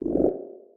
Sfx_creature_penguin_waddle_voice_01.ogg